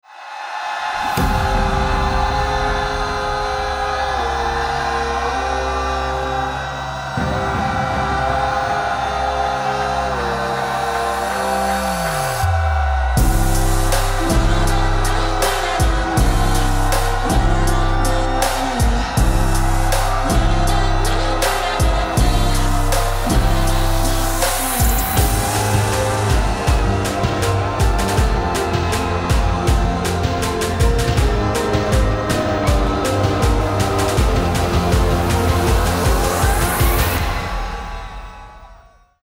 時折聴こえるハ～モニ～が、天界からの天使達からの祝福、支援、応援、声援、
カッコ良くグライコ掛けタ・・・・・ッッッ、ゲラゲラっっっ٩( ᐛ )و💨💨💨💥⚡💥⚡💥🎵🎶🎵🎶🎵